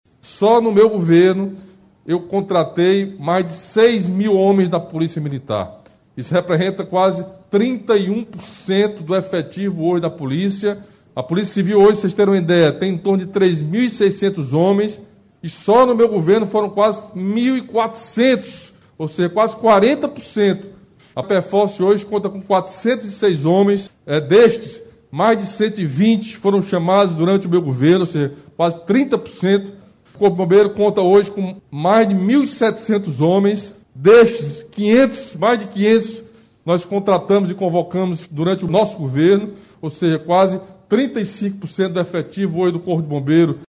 Em transmissão ao vivo via redes sociais na manhã desta quarta-feira (18), o governador do Ceará, Camilo Santana anunciou novos concursos públicos para a área da Segurança Pública do Ceará, com um total de 3.128 vagas, sendo 2.200 para Polícia Militar, 500 para a Polícia Civil, 170 para a Perícia Forense (Pefoce), e também autorizou o chamamento dos 258 aprovados nos últimos concursos do Corpo de Bombeiros.
Em sua fala, o governador destacou as contratações feitas durante os seus dois mandatos em todas as corporações.